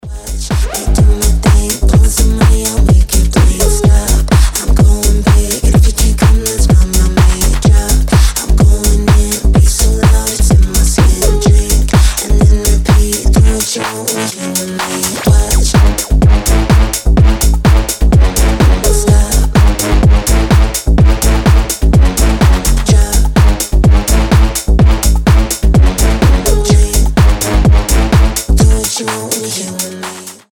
EDM
басы
чувственные
Tech House
сексуальный голос